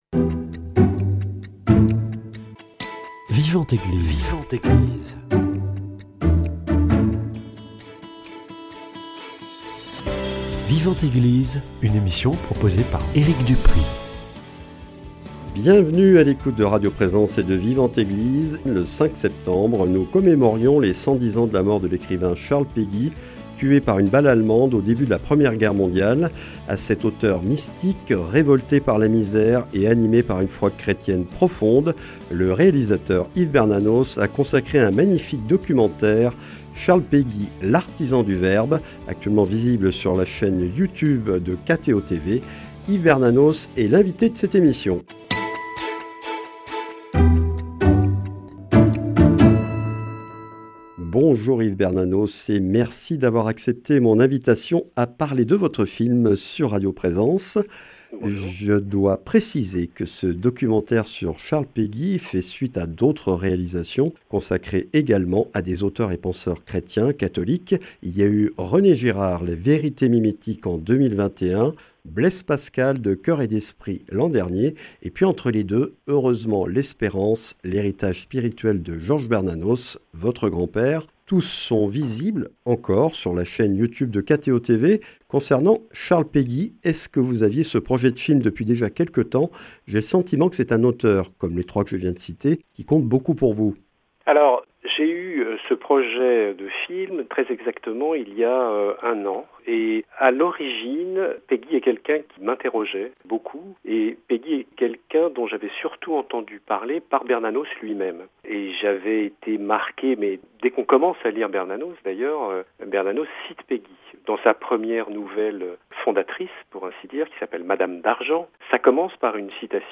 Entretien avec un artisan de l’image, réalisateur de films où souffle l’esprit des grands auteurs catholiques.